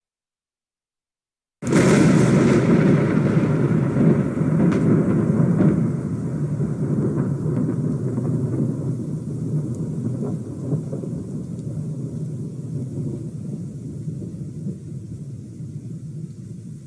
trovao1403.mp3